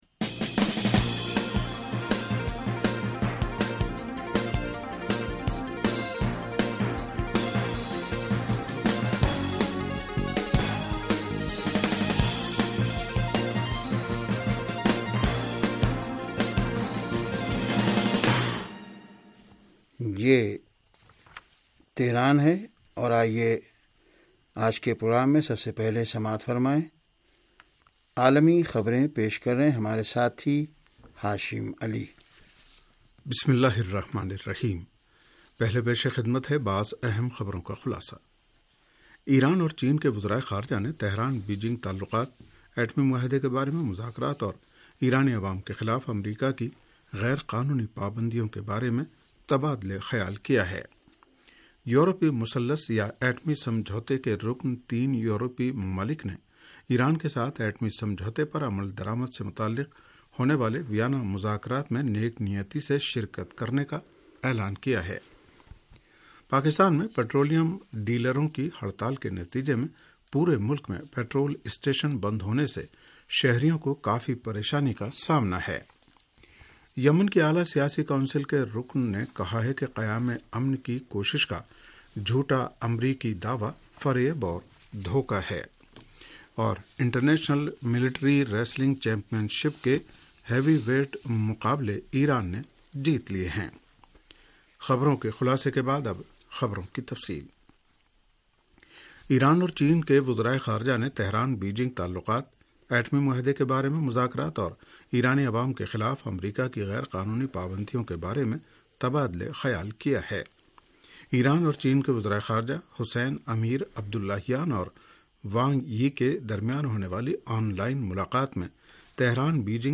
ریڈیو تہران کا سیاسی پروگرام گرد و پیش